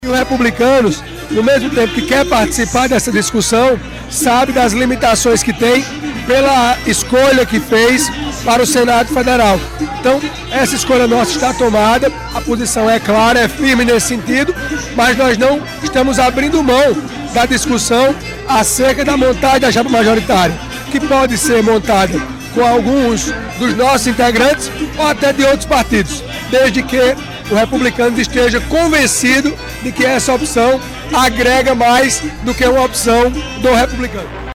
Os comentários de Motta foram registrados nesta segunda-feira (13/06), pelo programa Correio Debate, da 98 FM de João Pessoa.